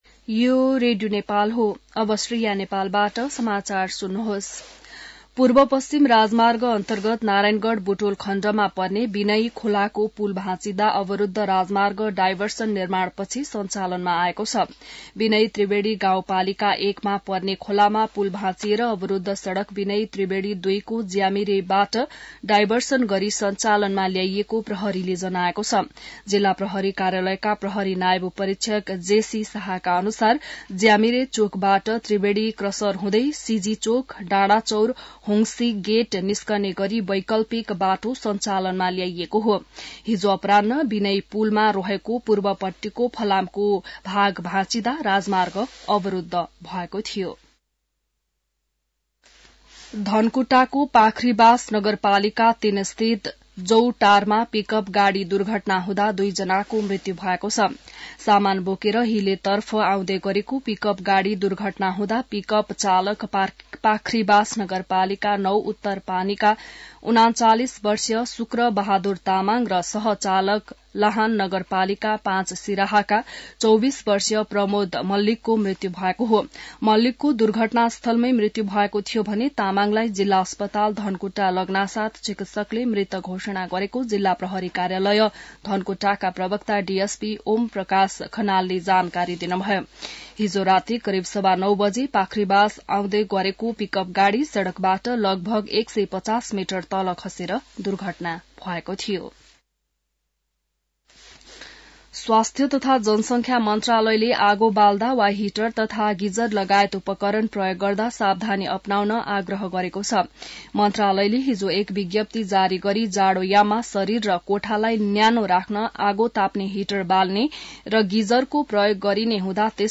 बिहान ६ बजेको नेपाली समाचार : २८ पुष , २०८१